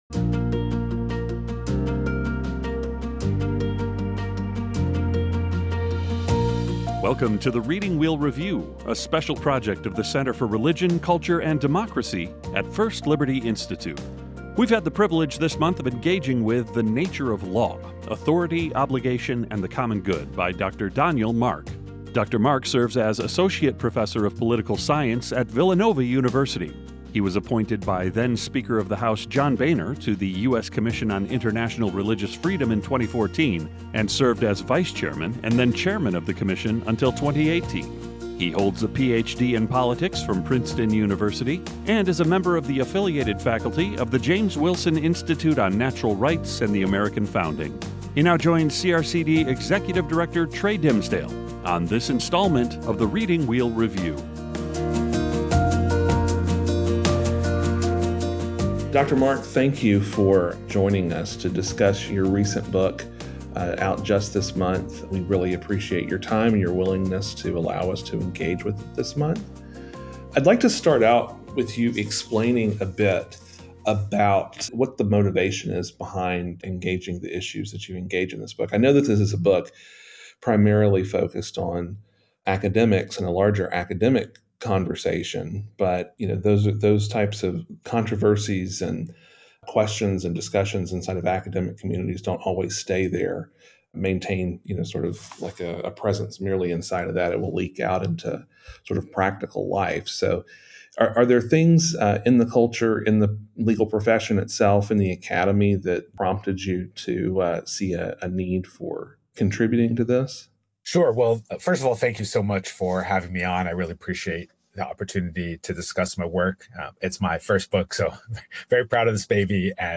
Interview | The Nature of Law - Center for Religion, Culture, and Democracy